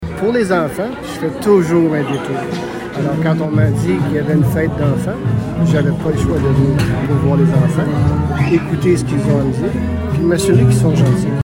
Samedi avait lieu la troisième édition du Noël des enfants de Messines.
Le père Noël a expliqué qu’il ne pouvait pas rater l’occasion de rencontrer les enfants :